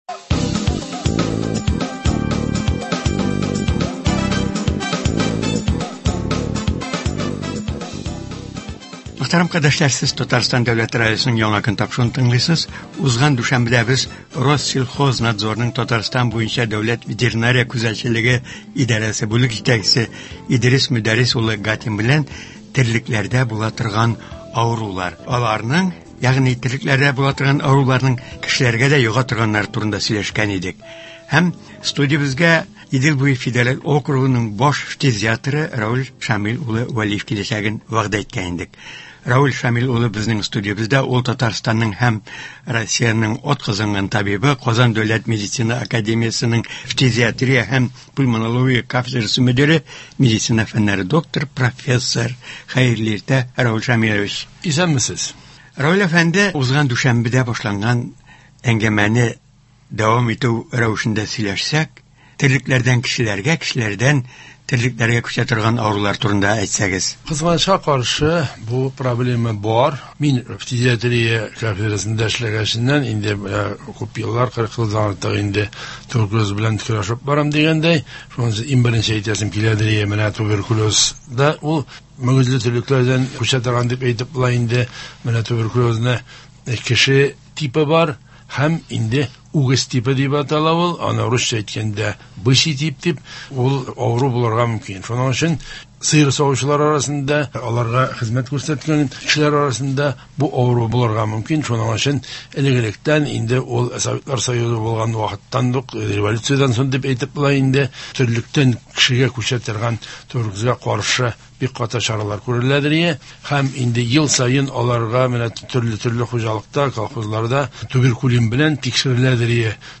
Туры эфир (11.10.23)